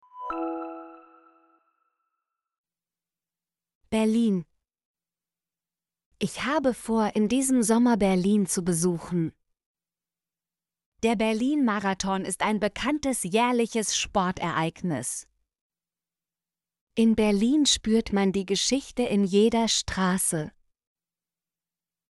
berlin - Example Sentences & Pronunciation, German Frequency List